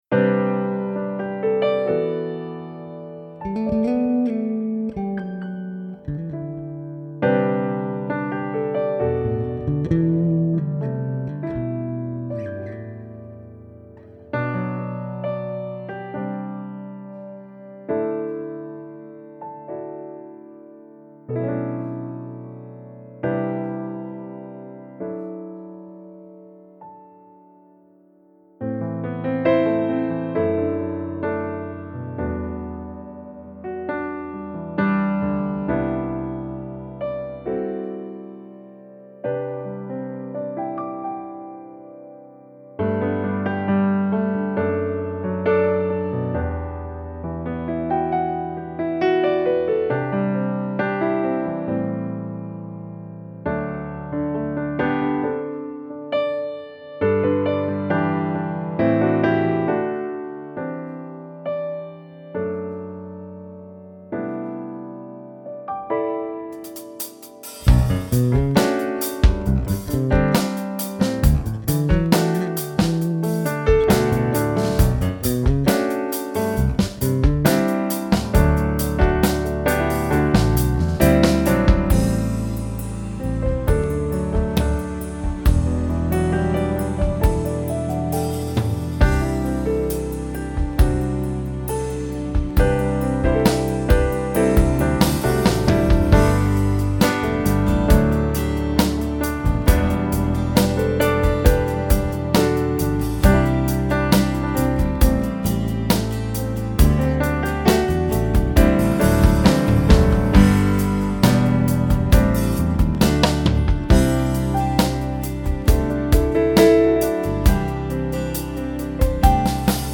• ein Mutmachlied